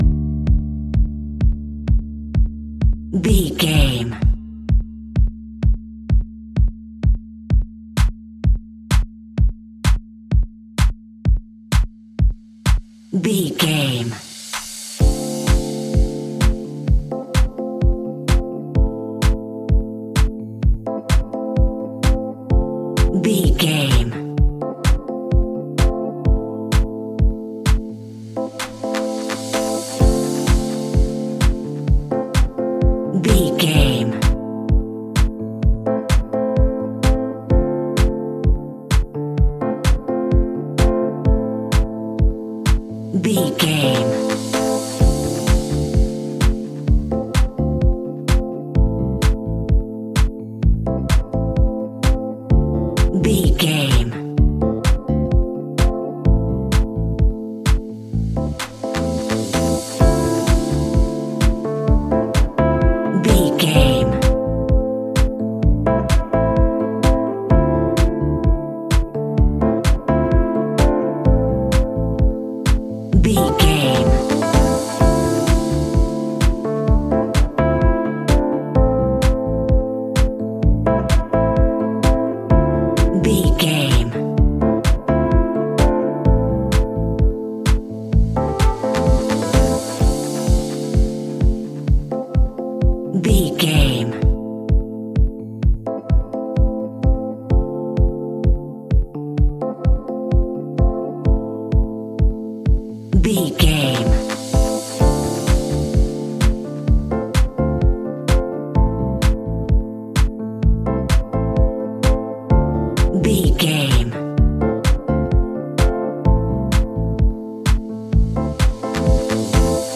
Aeolian/Minor
groovy
uplifting
driving
energetic
drums
drum machine
synthesiser
electric piano
bass guitar
funky house
deep house
nu disco
upbeat
instrumentals